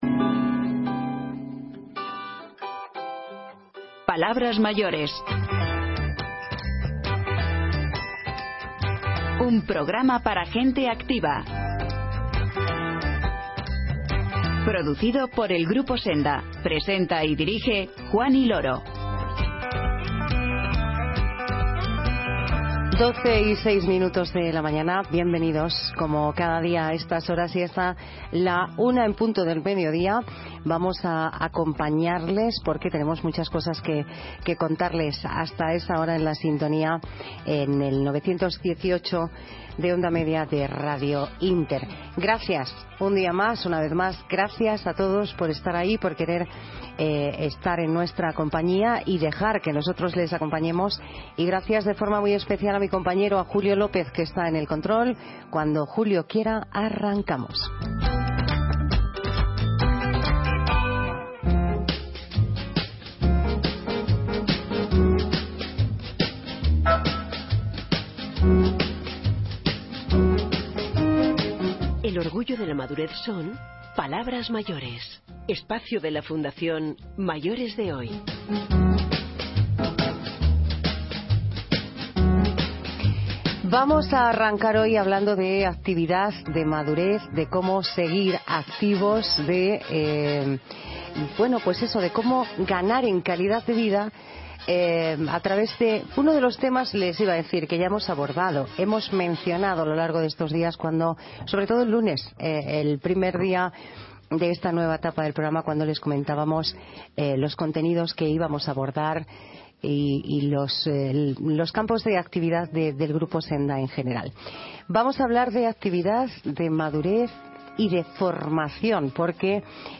Y, en tiempo de recuerdos, volvemos la mirada al año 1956 para escuchar sus voces y sus sonidos.